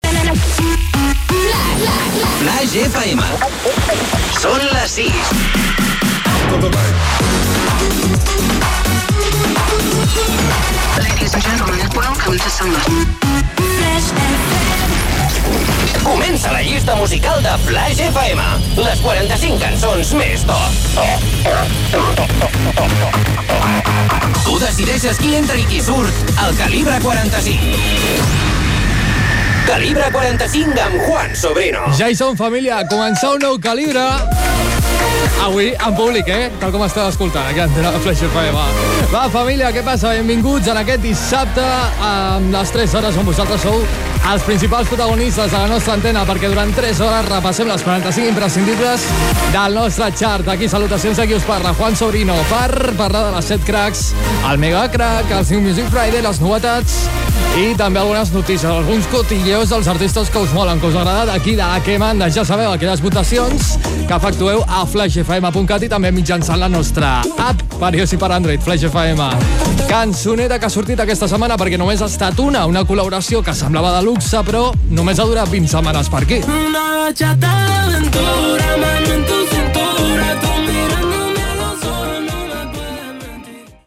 Indicatiu de l'emissora, hora, careta del programa, presentació, com participar-hi, novetat a la llista
Musical